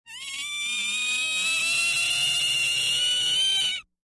Scream girls: